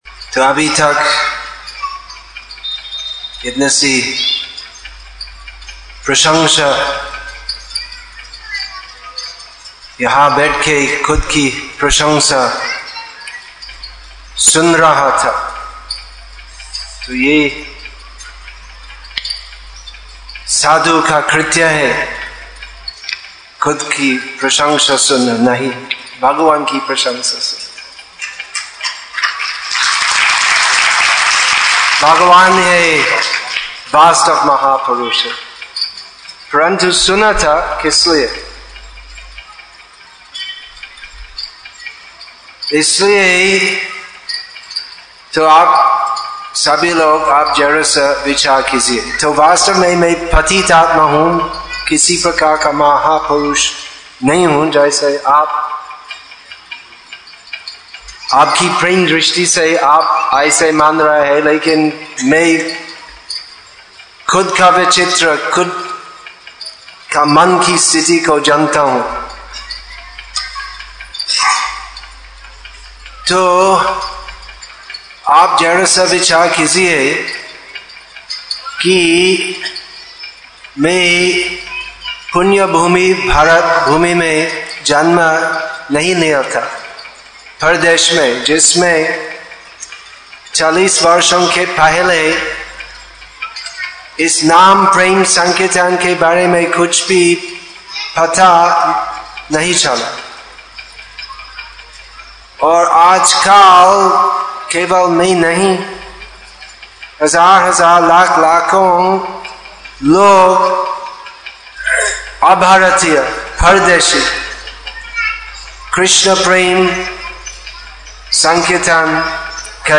पत्रकार परिषद